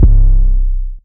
Jugg 808.wav